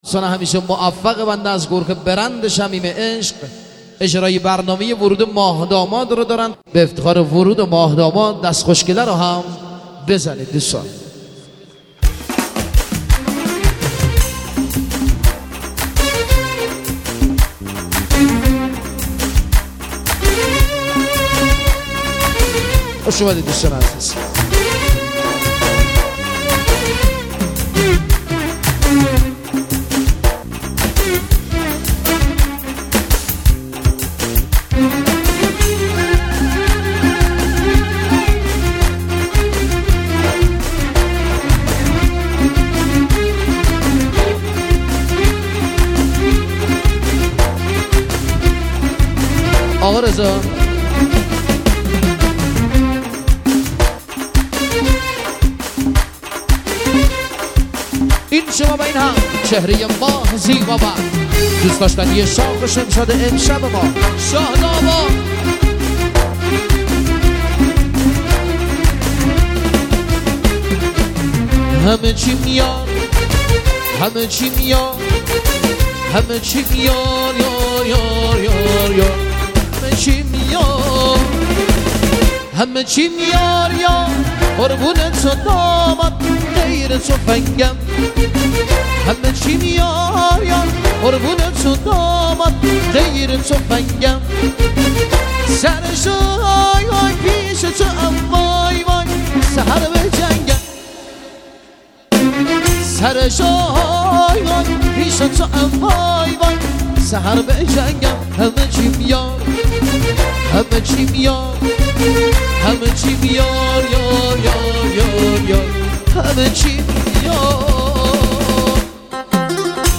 ترانه محلی